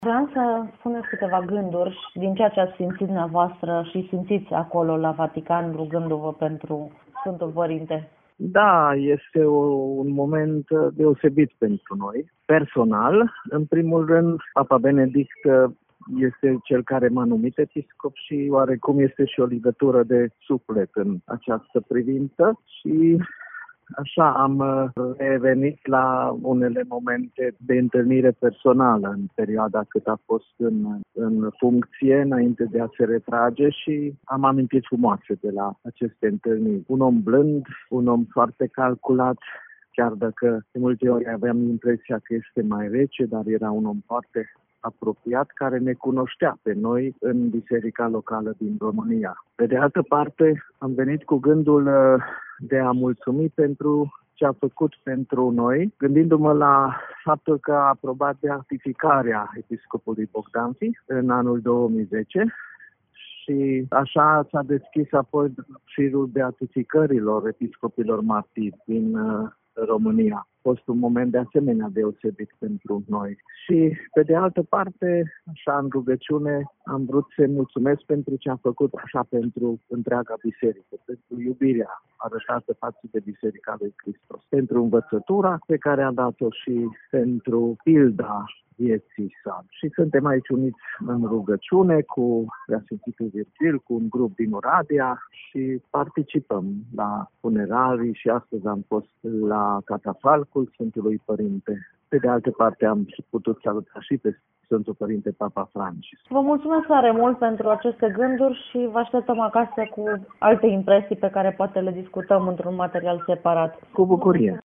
declaratie-papa-Bocskei.mp3